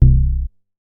MoogDown 009.WAV